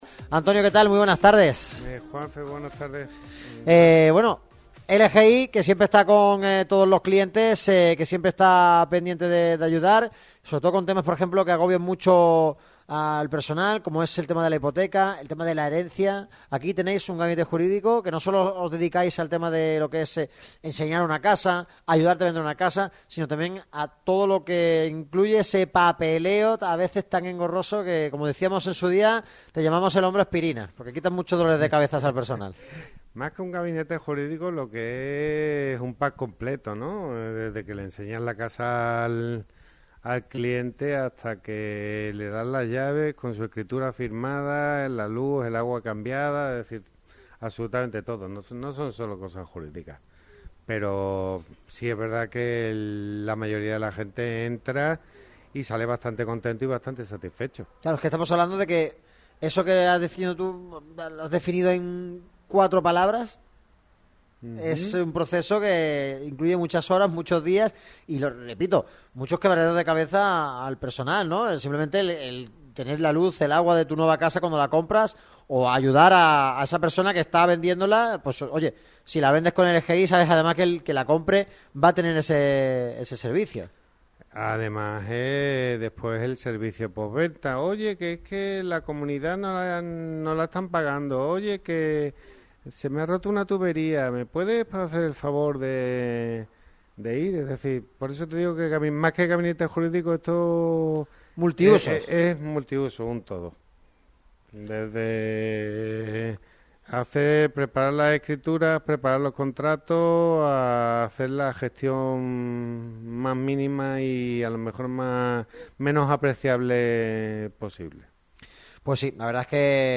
Todo el equipo de Radio MARCA Málaga ha acudido a la oficina de Lucía Gestión Inmobiliaria en la calle Héroe de Sostoa para realizar un nuevo programa y tratar toda la actualidad del deporte malagueño.